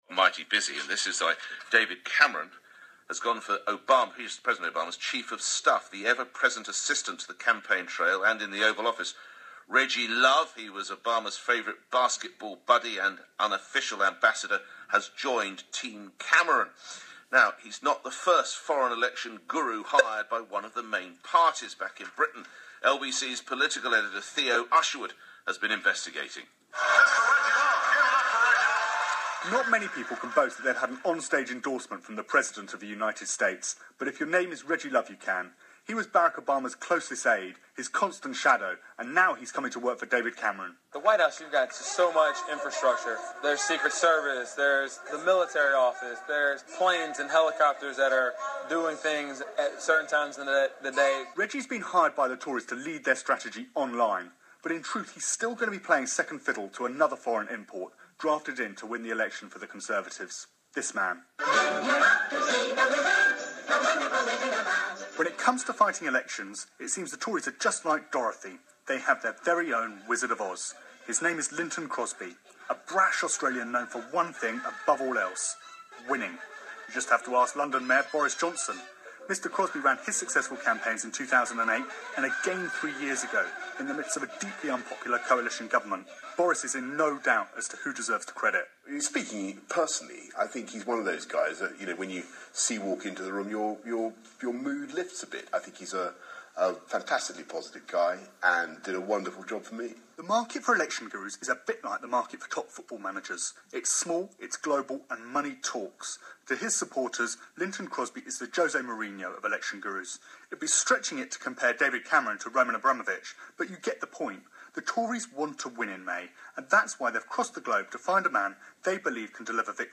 A package on the use of foreign campaign managers by political parties.